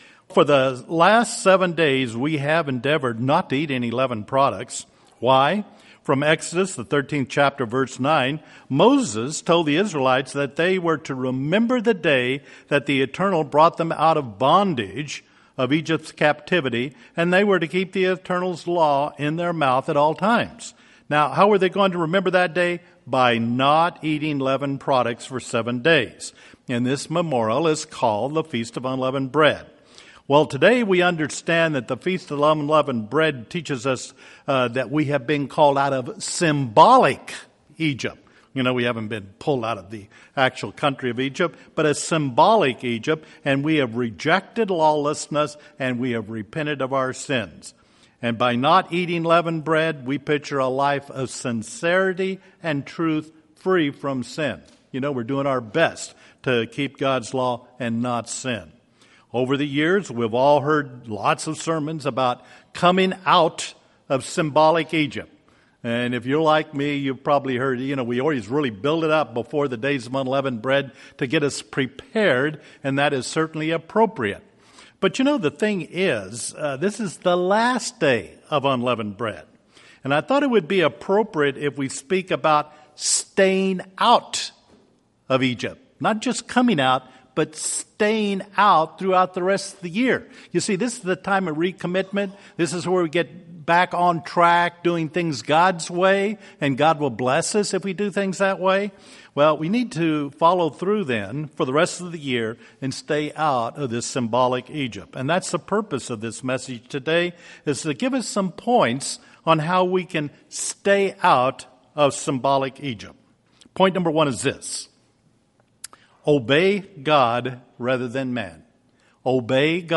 Print Do not take the easy way out Do not forsake the assembly of the brethren Do not follow the crowd Do not give up UCG Sermon Studying the bible?